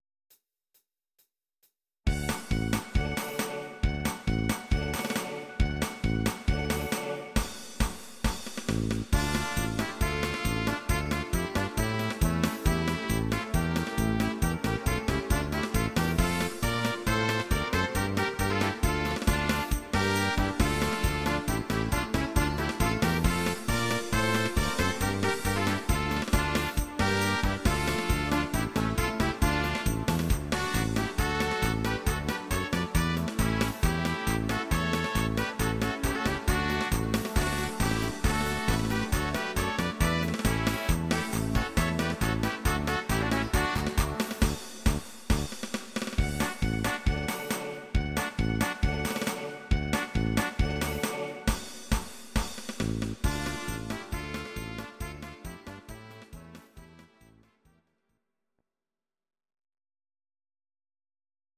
Audio Recordings based on Midi-files
German, Traditional/Folk